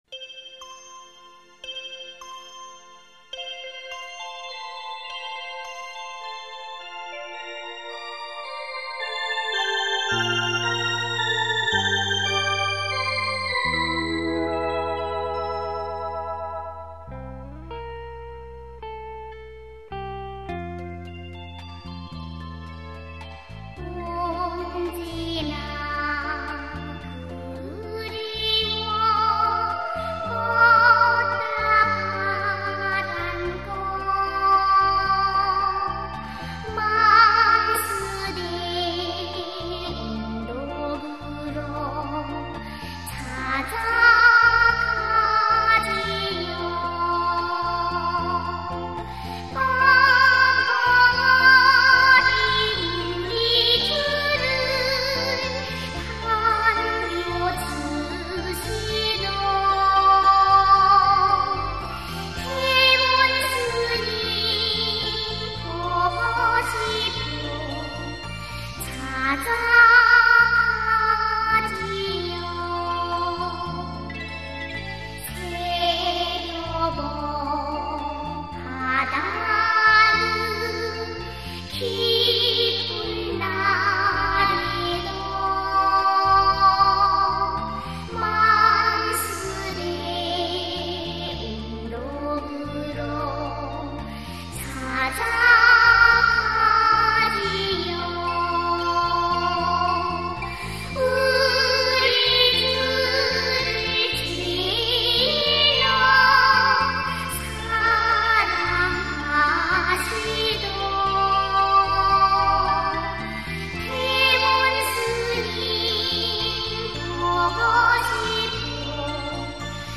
Korean Children's music